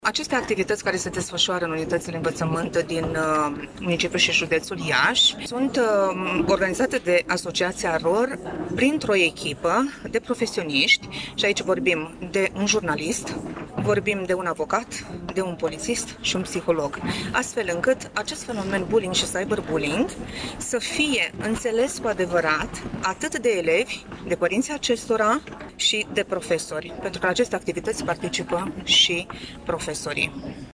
În Sala „Vasile Pogor” a Primăriei Iași, s-a desfășurat, astăzi, conferința cu tema ”Fenomenul bullying și Cyberbullying” în unitățile de învățământ din regiunea Nord-Est.